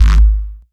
Desecrated bass hit 01.wav